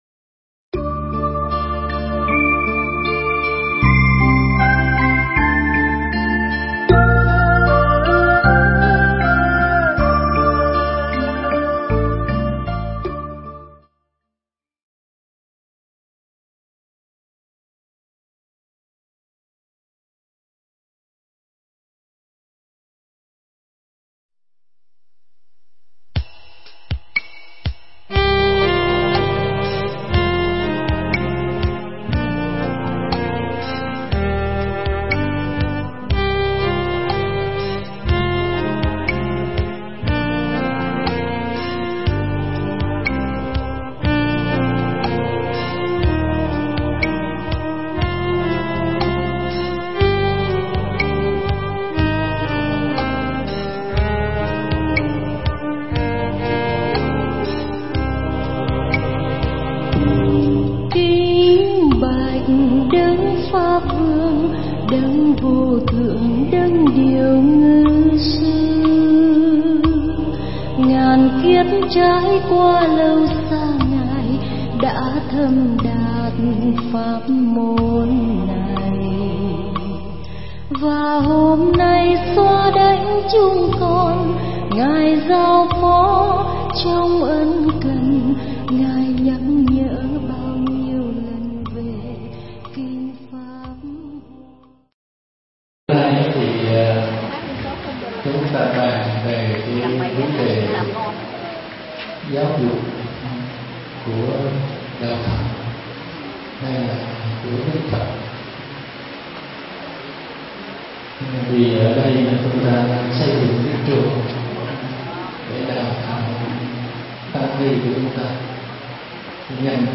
Mp3 Thuyết Pháp Giáo dục của Phật
giảng tại Học Viện Phật Giáo Việt Nam, TP.HCM